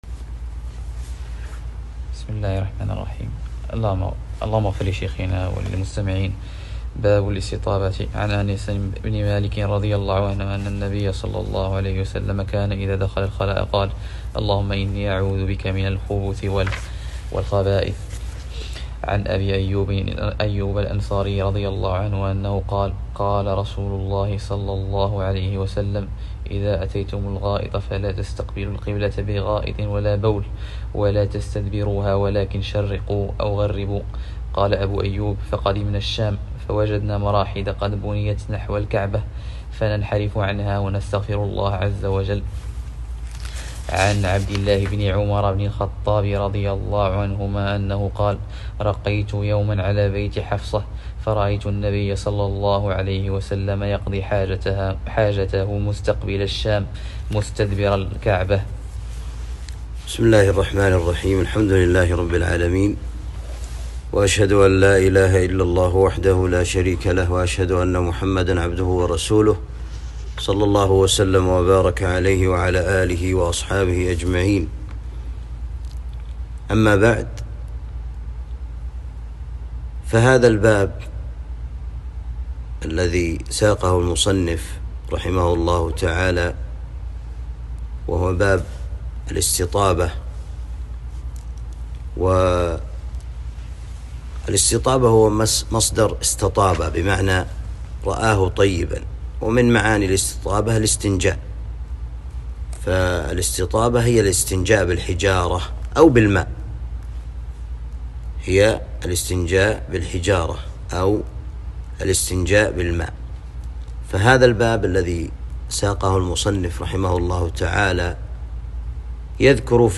4. الدرس الرابع شرح عمدة الأحكام